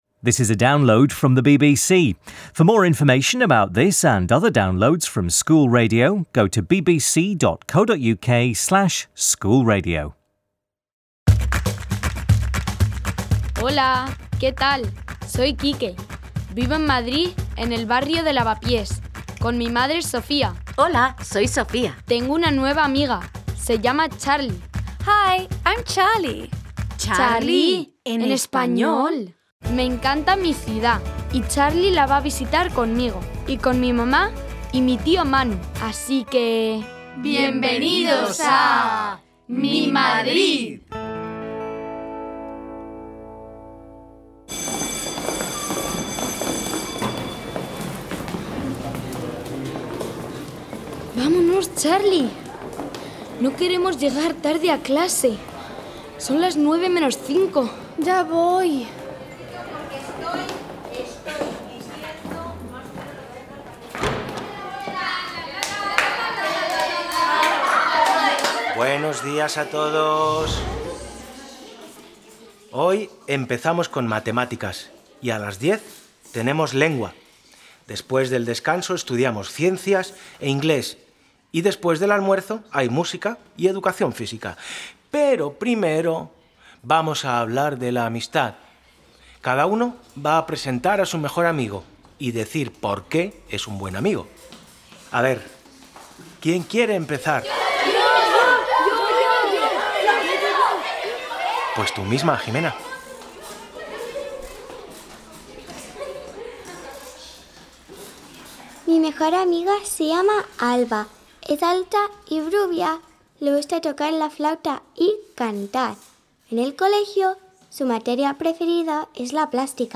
Charlie and Quique spend the morning at school and all the pupils talk about their best friends. After lunch Sofía tells them a story about people who are different but who are still best friends and Uncle Manu also sings about friendship. Key vocabulary and grammar focuses on school subjects and help with describing other people.